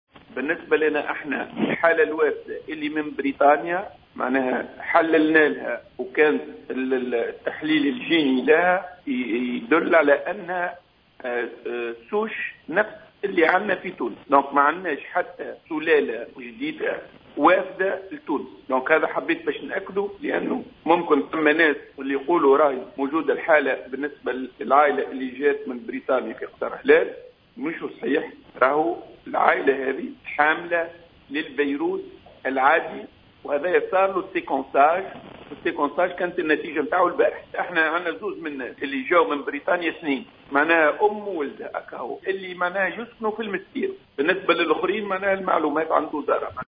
أفاد المدير الجهوي للصحة بالمنستير حمودة الببة في تصريح للجوهرة أف أم، اليوم الأربعاء، أنّ الحالات الوافدة من بريطانيا المصابة بكورونا، خضعت للتقطيع الجيني للكشف عن سلالة الفيروس، لتُثبت التحاليل أنّها حاملة لنفس الفيروس الموجود في تونس، وليس الفيروس المتحوّر (السلالة الجديدة).